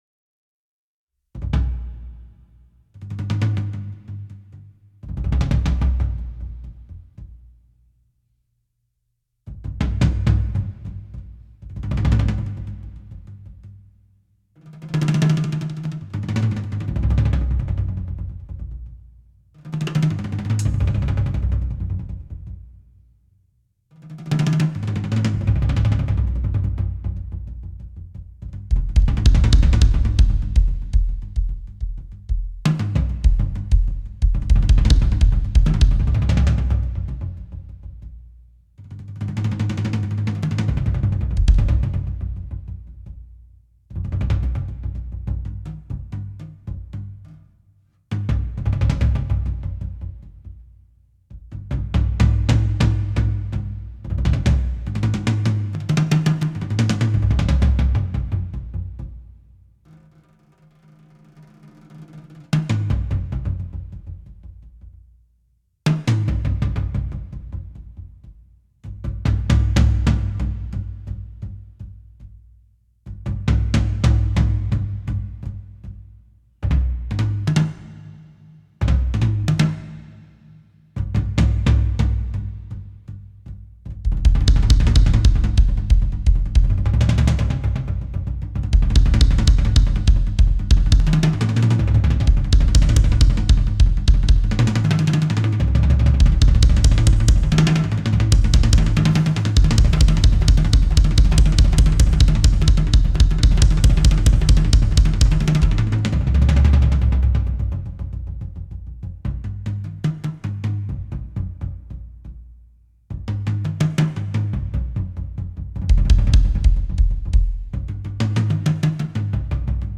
Moody Dark Tom Exploration